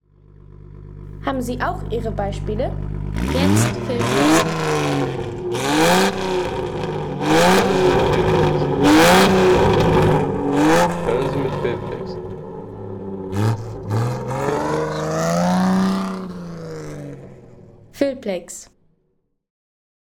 Alvis Silver Eagle 16/95 Sound | Feelplex
Ein Roadster-Sound direkt aus der klassischen Autoära
Klassischer Alvis Silver Eagle Roadster-Sound von 1934 mit starkem Vintage-Autocharakter.